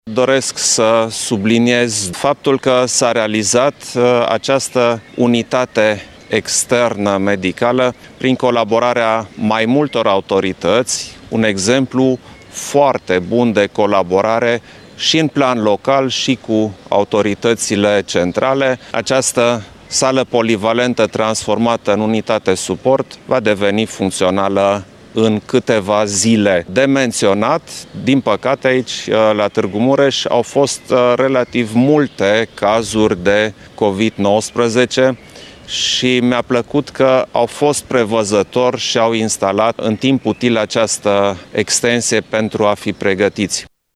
Declarațiile au fost făcute de președintele Iohannis astăzi cu prilejul vizitei sale la Tg.Mureș.